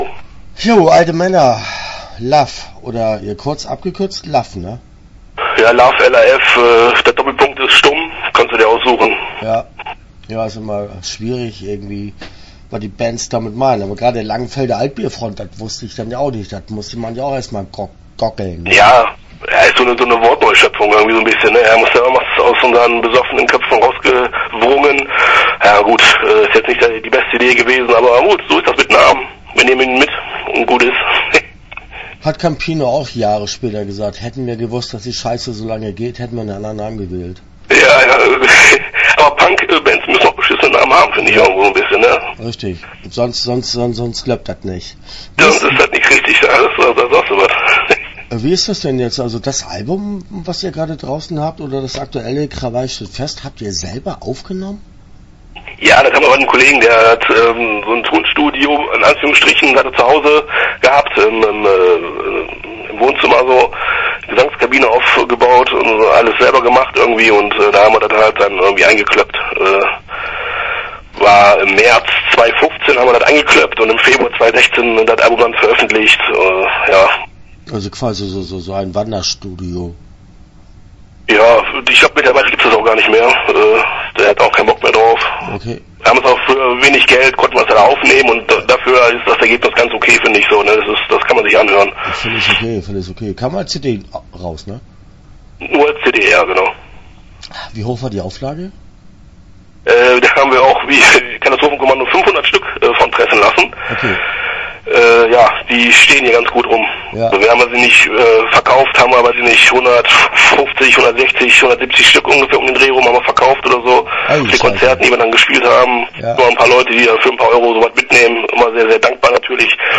L:AF - Interview Teil 1 (9:08)